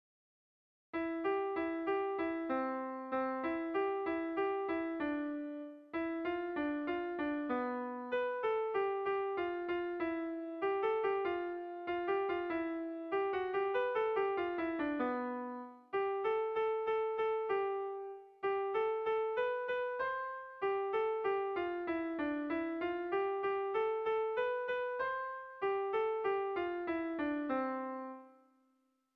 Irrizkoa
Gipuzkoa < Basque Country
Zortziko txikia (hg) / Lau puntuko txikia (ip)